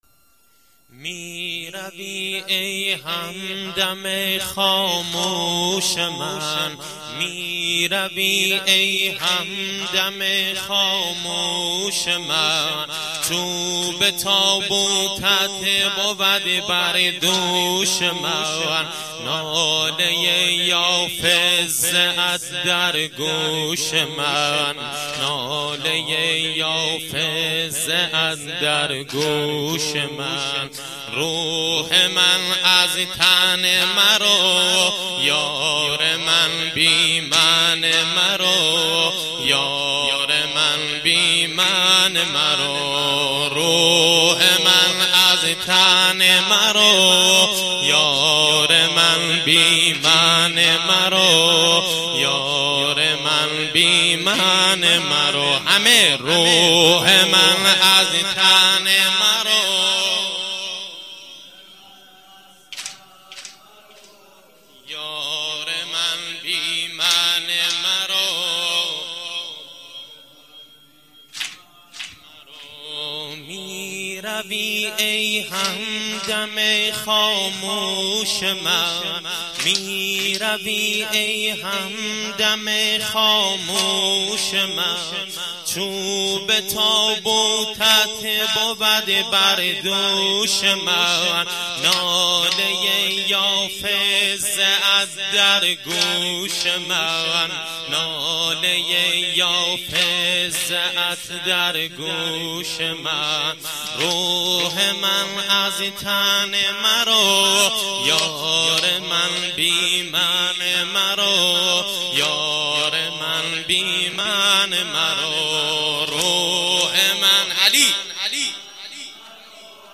واحد - میرویی ای هم دم خاموش من